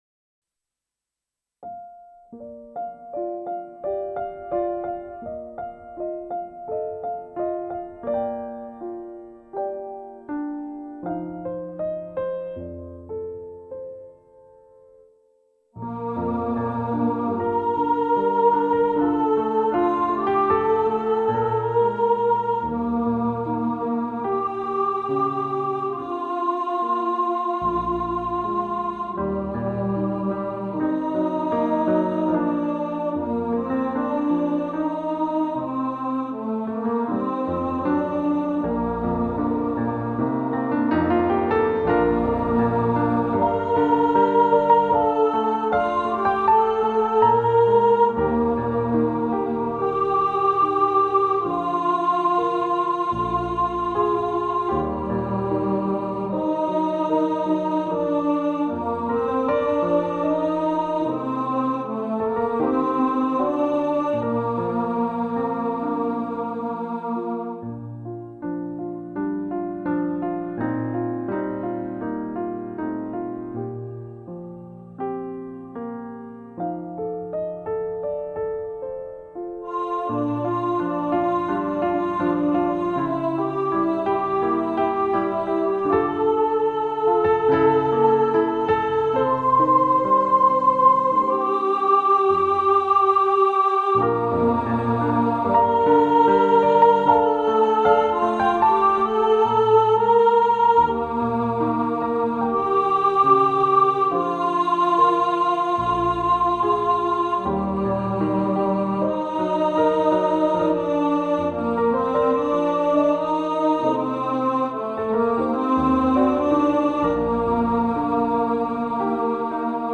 Over The Rainbow Soprano | Ipswich Hospital Community Choir